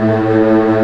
Index of /90_sSampleCDs/Giga Samples Collection/Organ/WurlMorton Brass